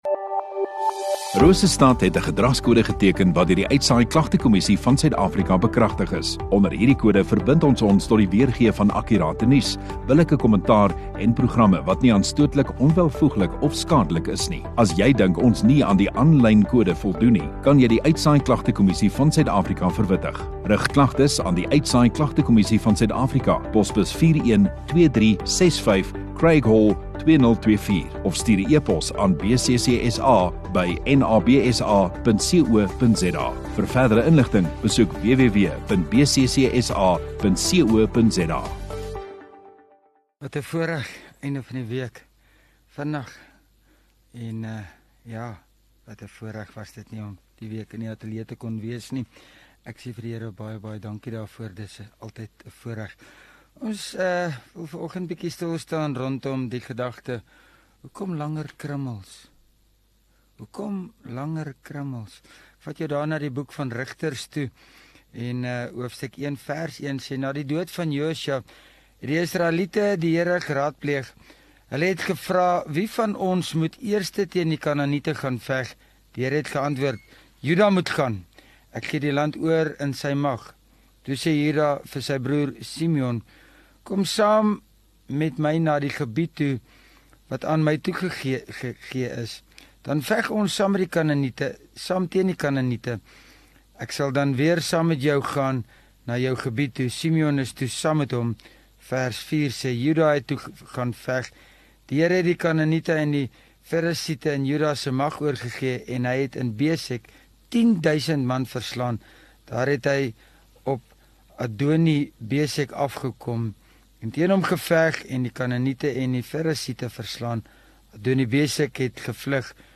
29 Aug Vrydag Oggenddiens